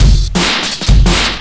LOOP18--01-L.wav